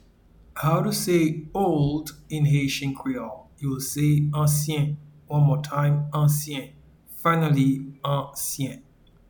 Pronunciation and Transcript:
Old-in-Haitian-Creole-Ansyen.mp3